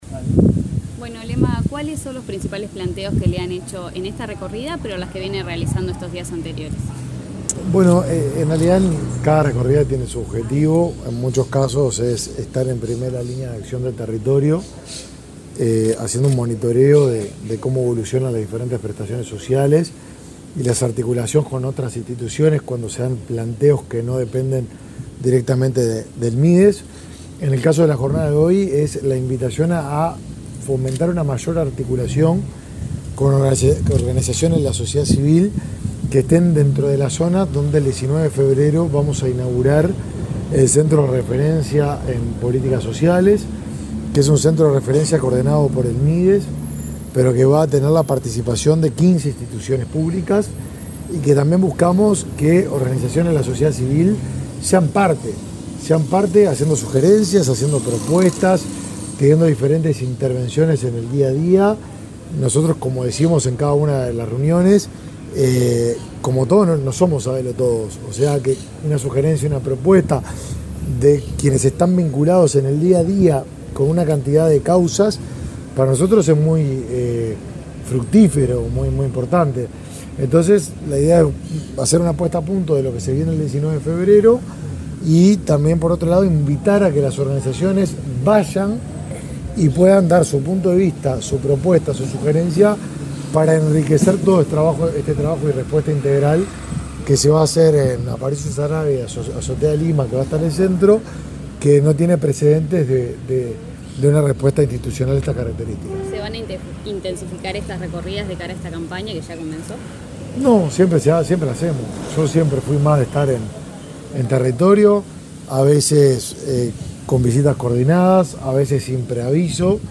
Declaraciones del ministro de Desarrollo Social, Martín Lema
Declaraciones del ministro de Desarrollo Social, Martín Lema 11/01/2024 Compartir Facebook X Copiar enlace WhatsApp LinkedIn El ministro de Desarrollo Social, Martín Lema, dialogó con la prensa, durante una recorrida por distintos centros de Montevideo en los que se atiende a población vulnerable.